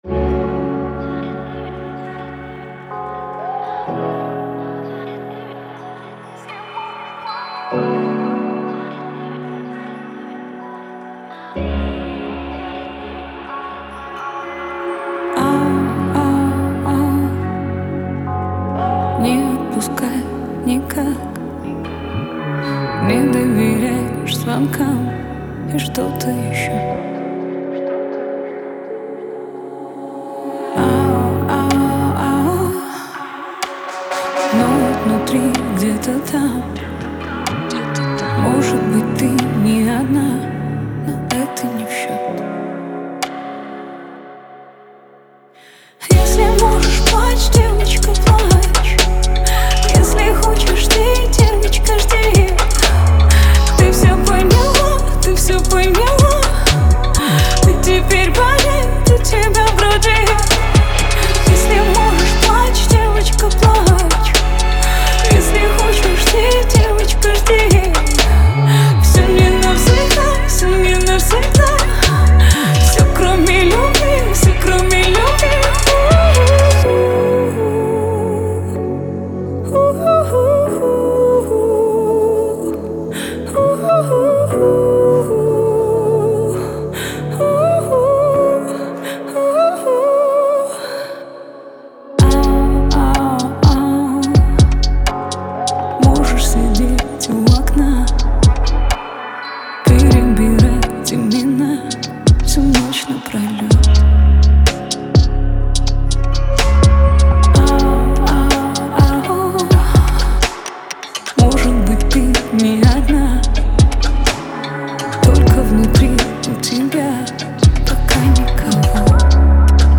это яркий пример поп-рока с элементами фолка